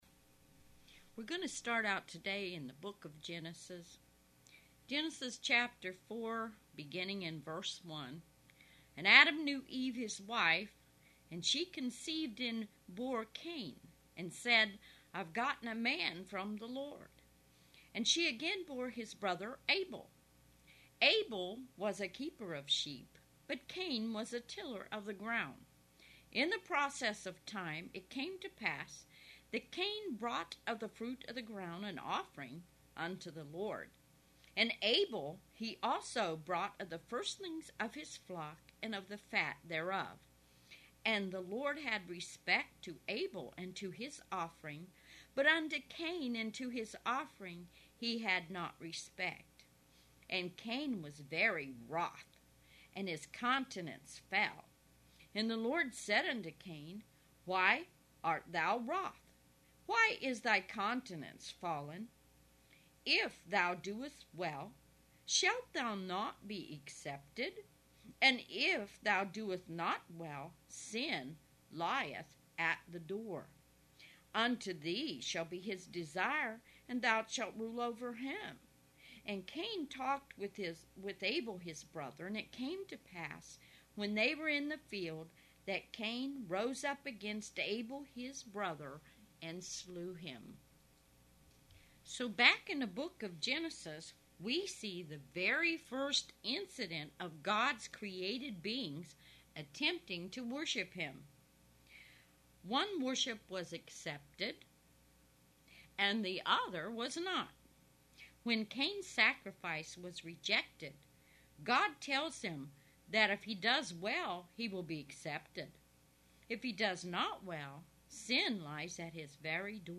Message given on July 13, 2008 audio version